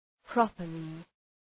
Προφορά
{‘prɒpərlı}